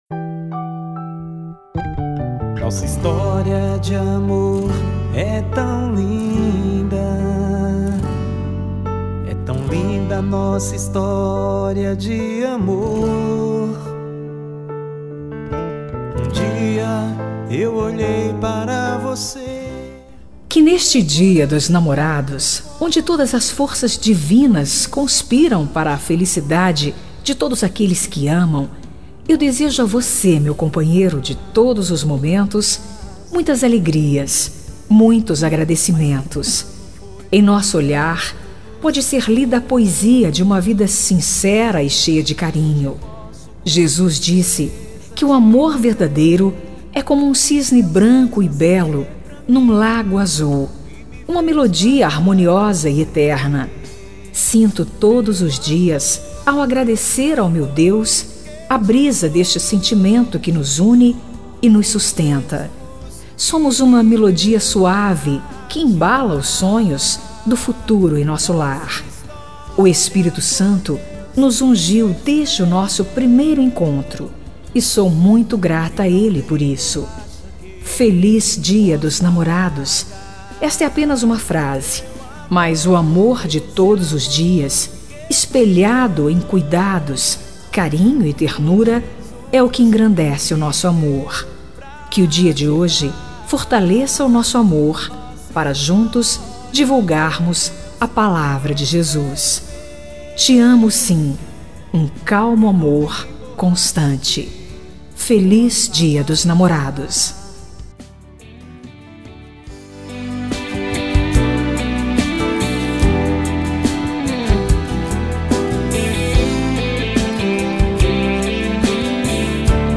Voz Feminina
Código: 111116 – Música: Nacional Evangélica – Artista: Desconhecido
10-Esposo-Evangelico-fem.-17-Musica-Romantica-evangelica.mp3